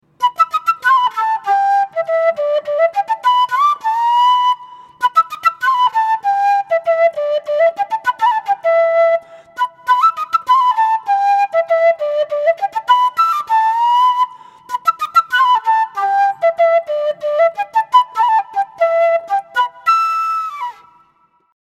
Кена Wari G Тональность: G
Достаточно отзывчивая модель кены, стабильно звучащая во всех трех октавах. Возможны незначительные отклонения по строю в верхнем диапазоне.
Кена - продольная флейта открытого типа, распространённая в южноамериканских Андах.